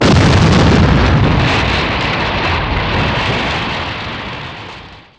explrg03.mp3